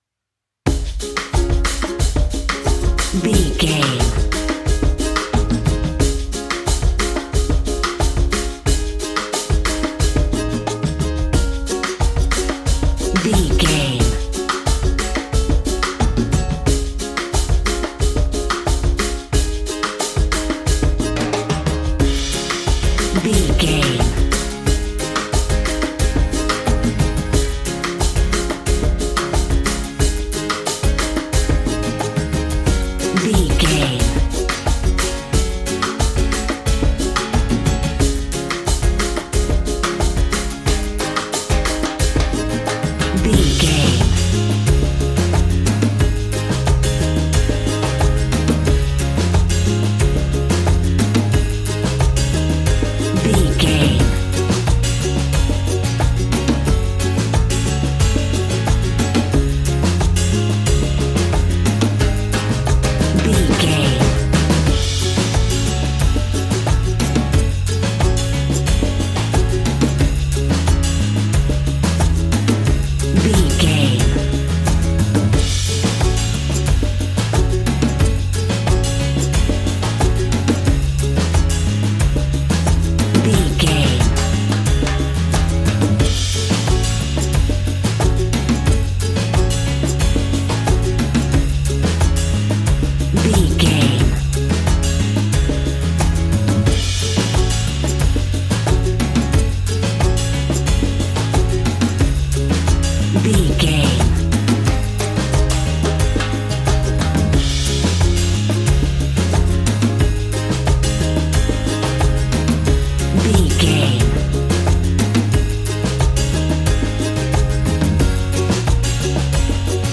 Aeolian/Minor
D♭
cheerful/happy
mellow
drums
electric guitar
percussion
horns
electric organ